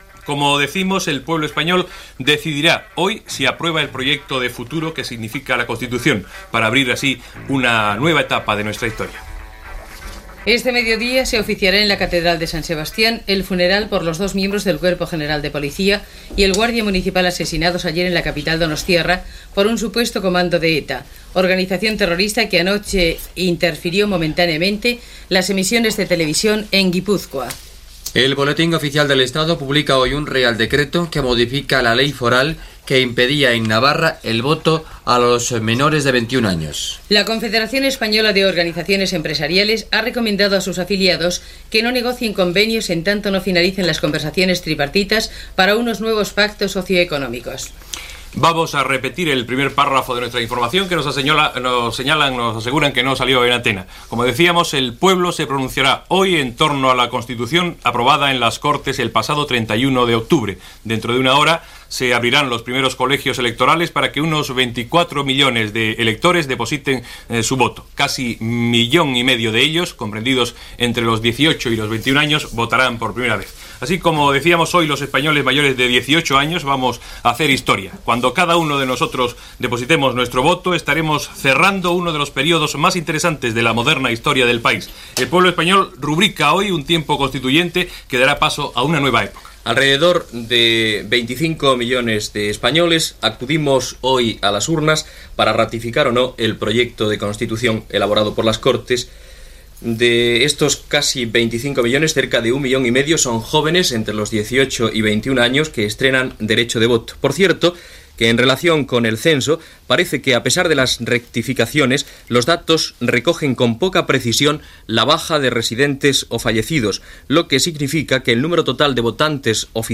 d36f72575aac88cf87ce23e338e4a944831be886.mp3 Títol Radio Nacional de España Emissora Radio Nacional de España Barcelona Cadena RNE Titularitat Pública estatal Nom programa España a las 8 Descripció Resum informatiu de la jornada i informació sobre el referèndum per a la ratificació de la Constitució Espanyola de 1978 que es va celebrar aquell dia. Entrevista telefònica a un bisbe que actuarà com a president d'una taula electoral.
Gènere radiofònic Informatiu